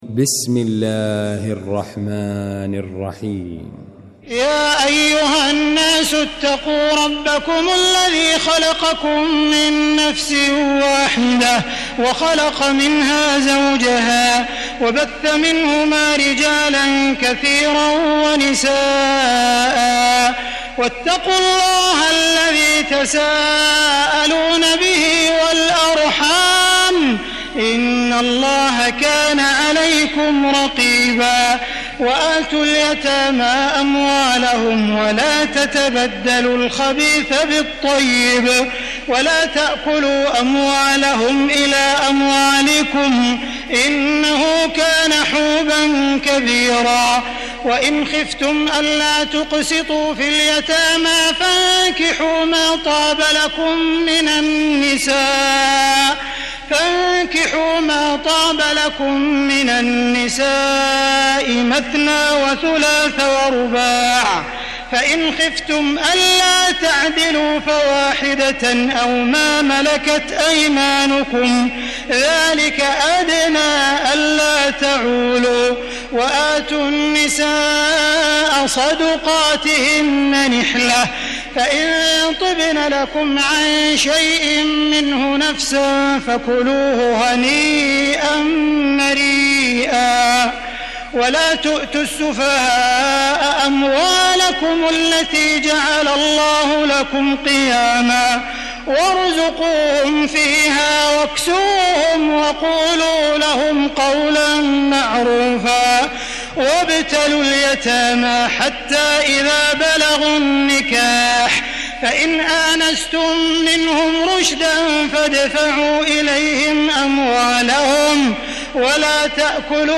المكان: المسجد الحرام الشيخ: خالد الغامدي خالد الغامدي معالي الشيخ أ.د. عبدالرحمن بن عبدالعزيز السديس فضيلة الشيخ عبدالله الجهني فضيلة الشيخ ماهر المعيقلي النساء